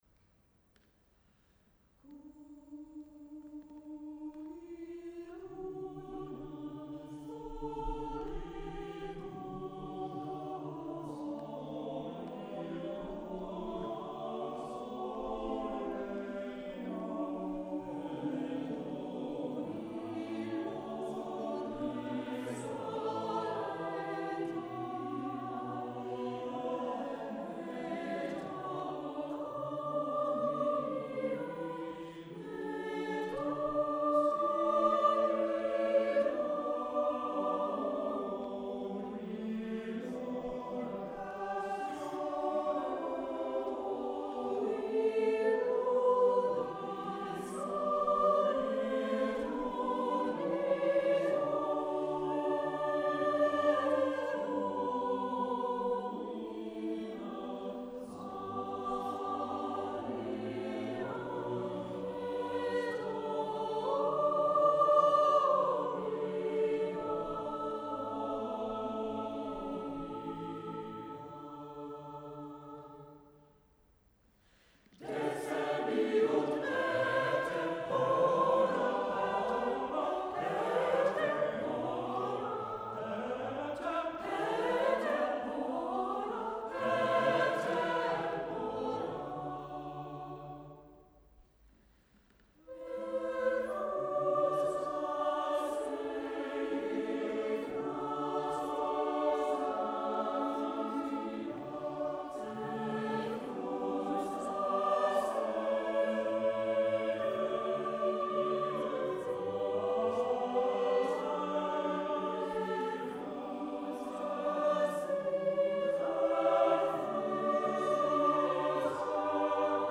Holy Rood Catholic Church, Abingdon Road, Oxford
His style can be very polished and sophisticated, but also lively, with double-choir interchanges of short phrases.